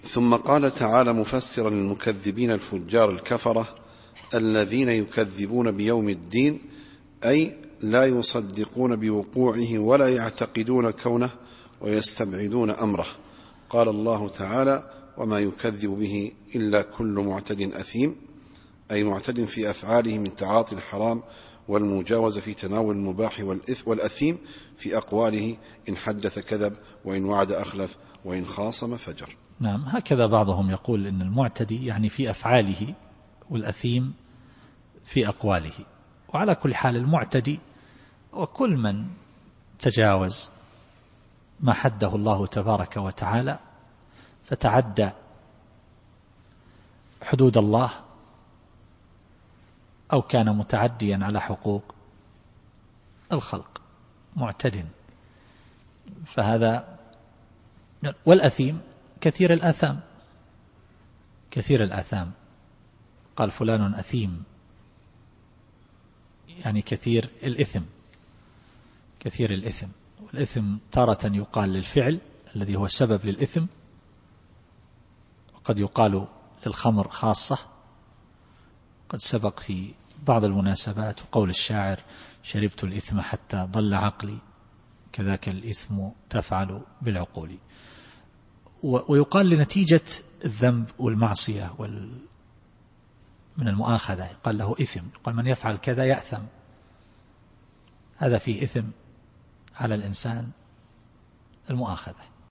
التفسير الصوتي [المطففين / 12]